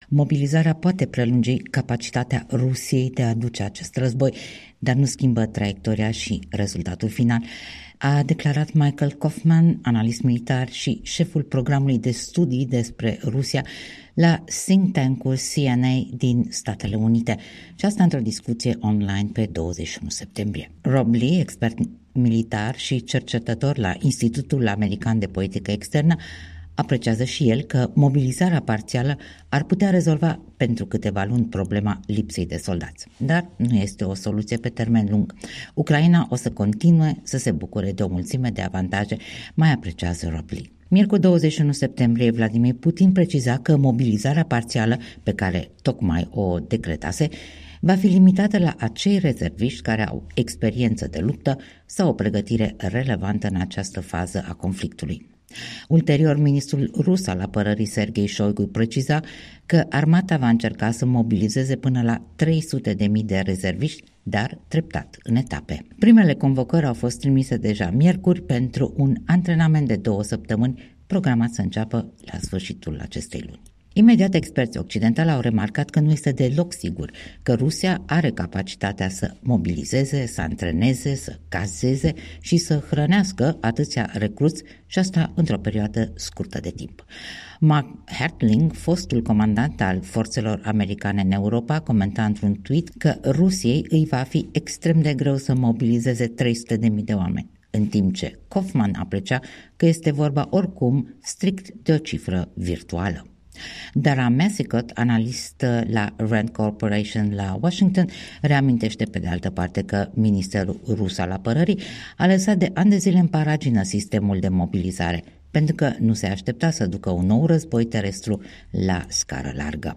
Analiză | Mobilizarea parțială nu va schimba radical cursul războiului din Ucraina